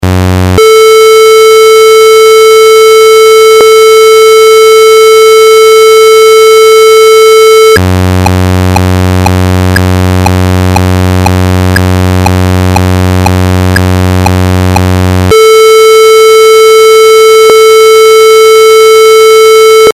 Ошибка звука в Windows